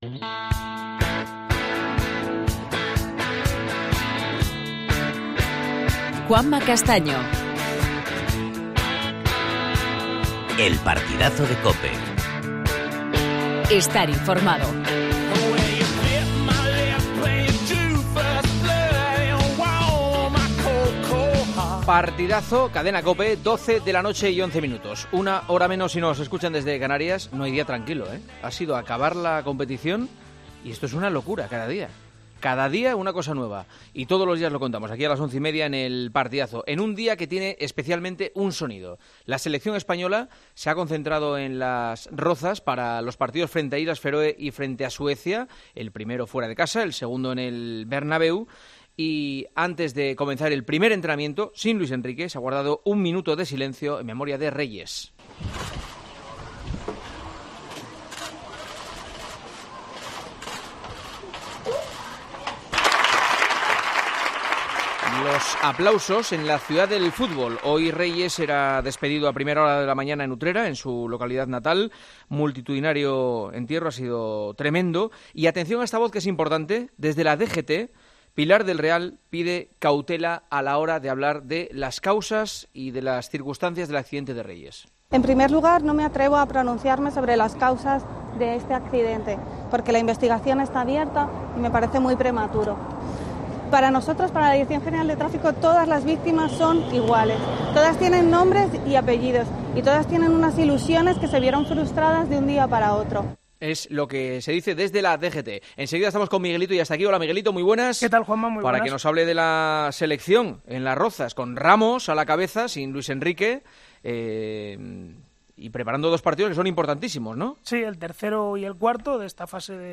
Los tertulianos de 'El Partidazo de COPE' exponen su opinión sobre la equipación que vestirá el club azulgrana la temporada 19/20